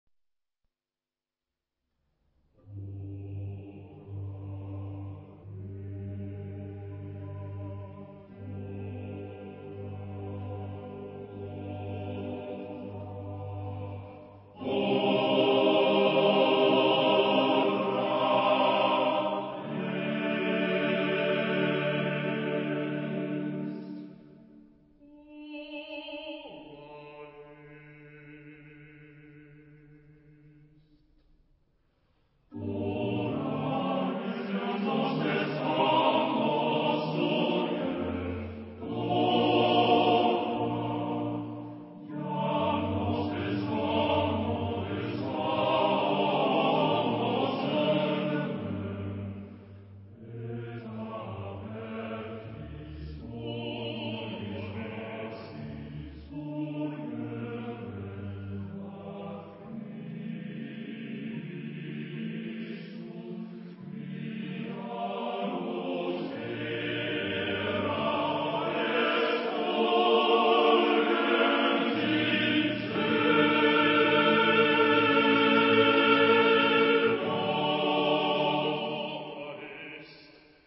Genre-Style-Form: Sacred ; Romantic ; Response
Mood of the piece: majestic
Type of Choir: SATB + SATB + SATB + SATB  (16 mixed voices )
Instrumentation: Organ OR Continuo  (2 instrumental part(s))
Instruments: Organ (1) ; Cello (1)